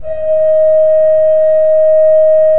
Index of /90_sSampleCDs/Propeller Island - Cathedral Organ/Partition K/KOPPELFLUT R